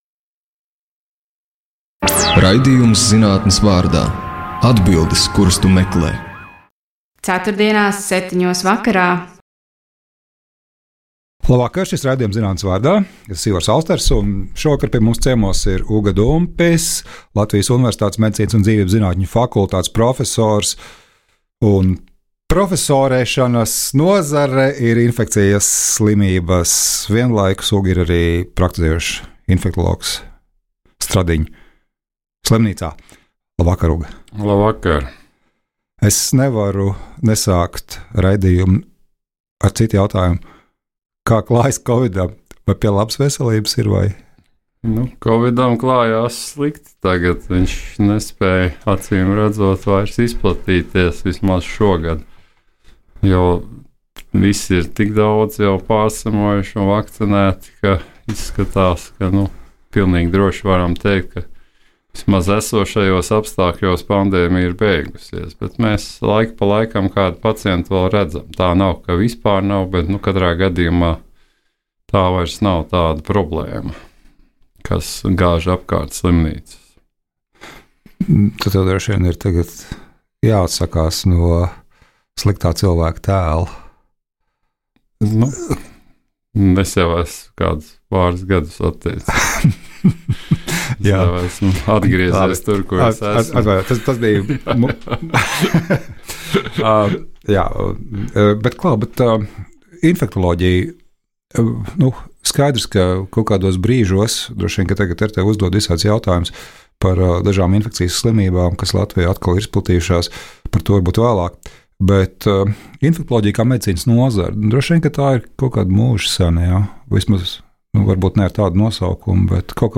Tā arī būs, taču saruna visu laiku aizklīdīs blakusceļos un taciņās.